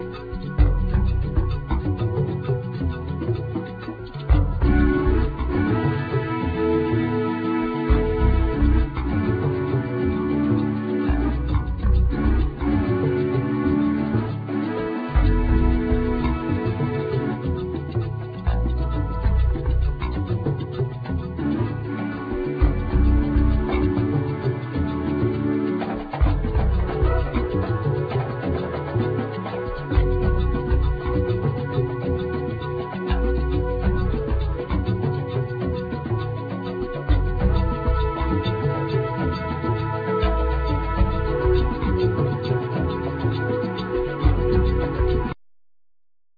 Trumpet,Keyboards
Percussion
Balafon
Djembe
Flute,Percussion,Vocal
Tama Soucou(Talking Drums)
Bara drum